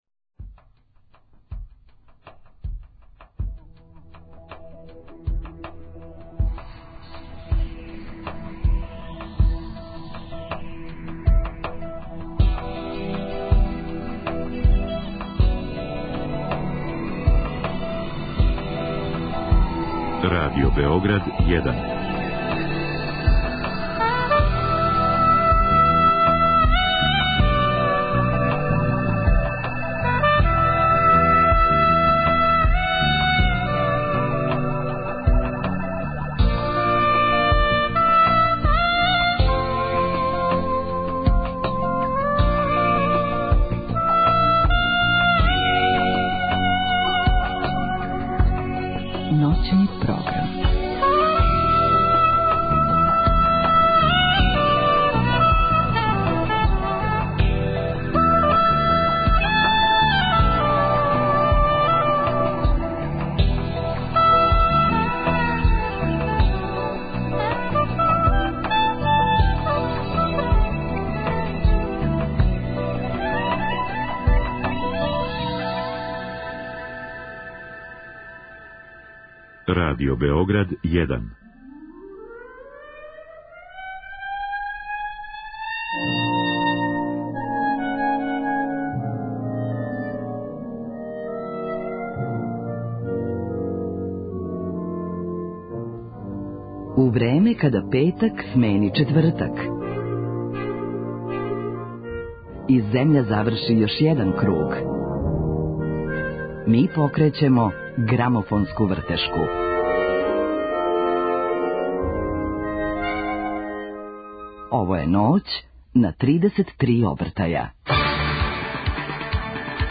Гост Ноћи на 33 обртаја биће наш чувени композитор Корнелије Ковач. Разговараћемо о новој песми Бисере Велетанлић, за коју је писао музику и аранжман, о његовом раду, али и о драгим мелодијама које ће поделити са нама у првом сату емисије.